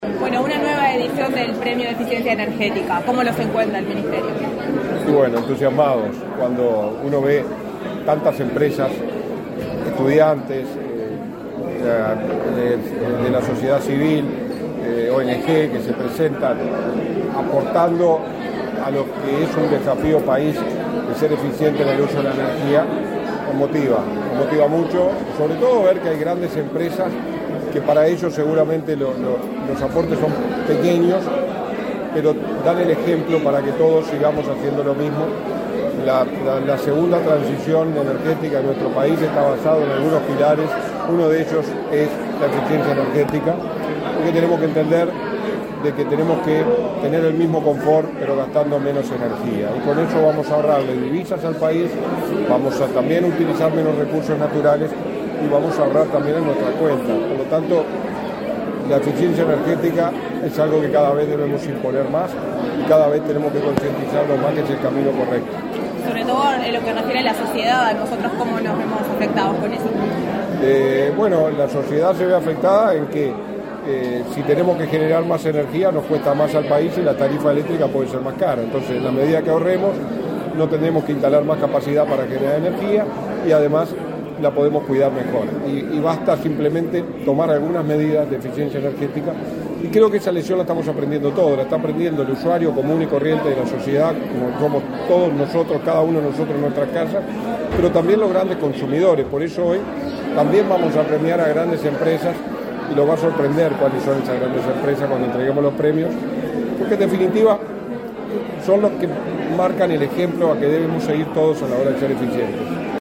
Entrevista al subsecretario del MIEM, Walter Verri
Entrevista al subsecretario del MIEM, Walter Verri 08/11/2023 Compartir Facebook X Copiar enlace WhatsApp LinkedIn Este 8 de noviembre se realizó la entrega del Premio a la Eficiencia Energética 2023. En la oportunidad, el subsecretario del Ministerio de Industria, Energía y Minería (MIEM), Walter Verri, realizó declaraciones a Comunicación Presidencial.